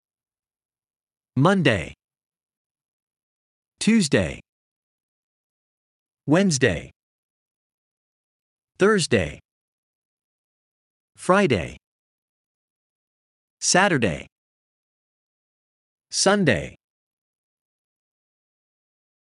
Aşağıdaki tabloda İngilizce günlerin isimleri verilmiştir. Ayrıca telaffuzlarını doğru öğrenmeniz için ses dosyası eklenmiştir.